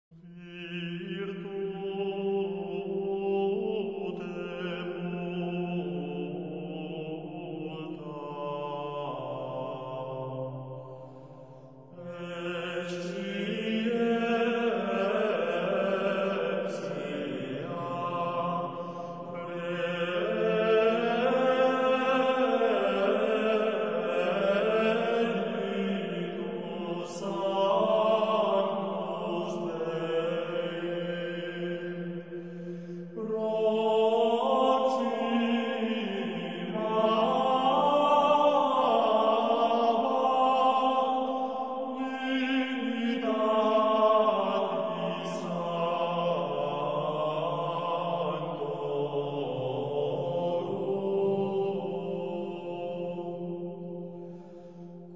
Традиция цистерцианского пения